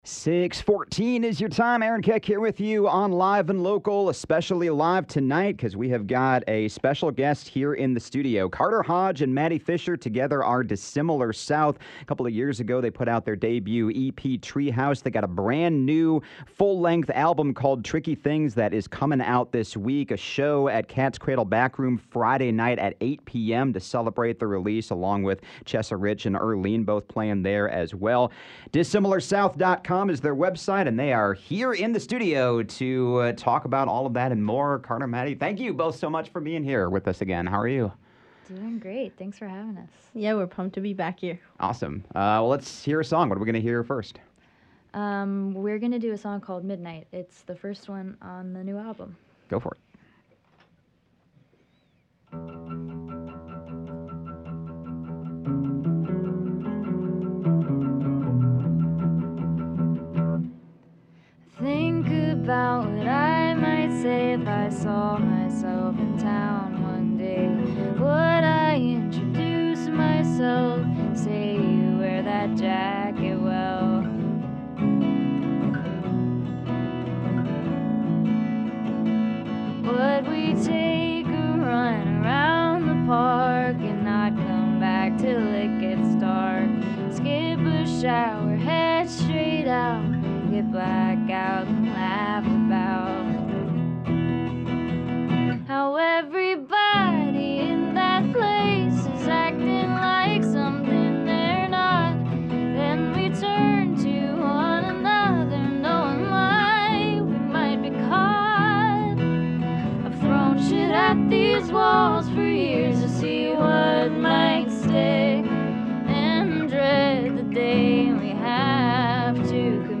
They also played three songs: